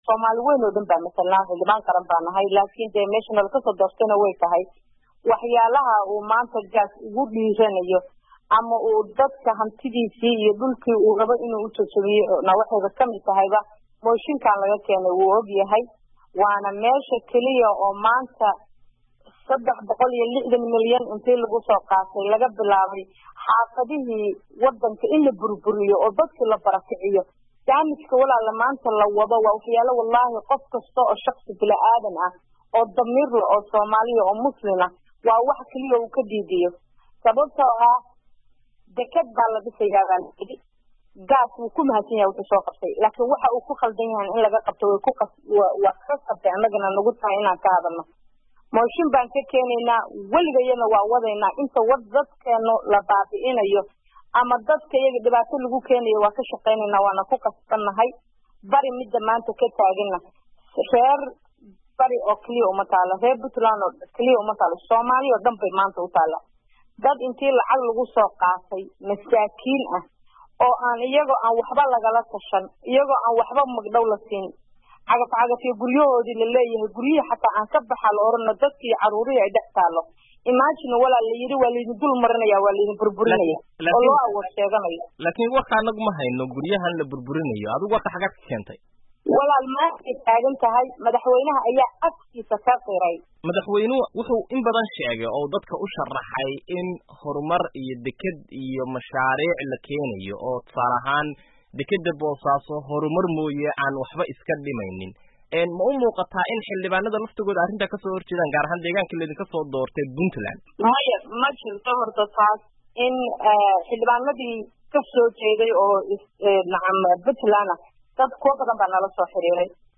Dhagayso VOA oo waraysatay Xildhibaanda Sacdiya